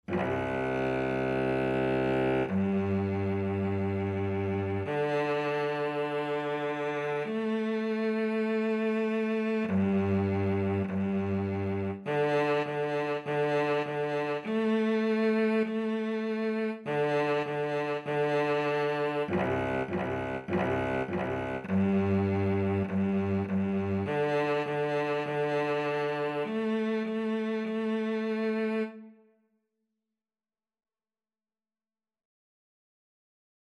4/4 (View more 4/4 Music)
C3-A4
Beginners Level: Recommended for Beginners
Cello  (View more Beginners Cello Music)
Classical (View more Classical Cello Music)